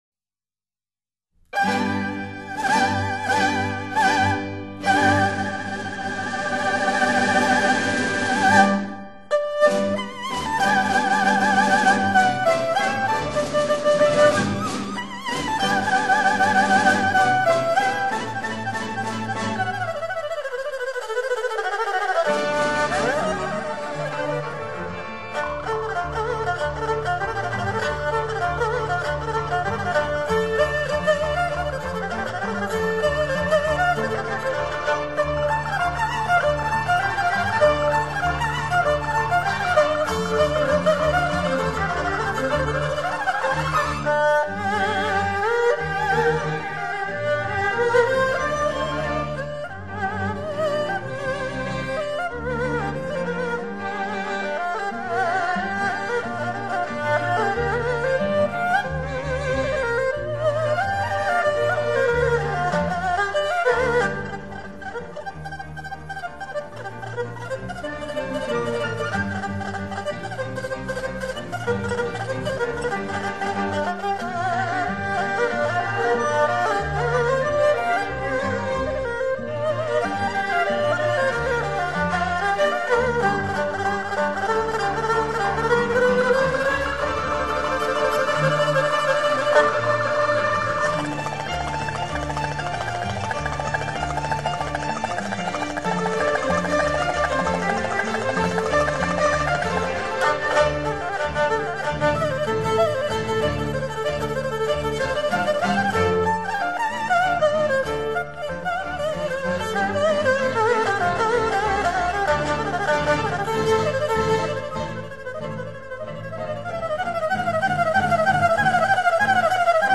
二胡新生代演奏家
二胡独特的音色，常会散发出浓郁的民族情怀，如泣如诉，而这种种特质让它与西方小提琴比较起来，更易打动人心。